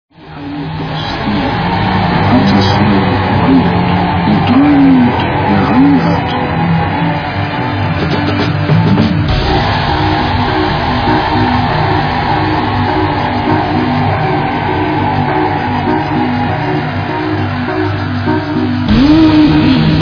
'dark neo-classical/industrial'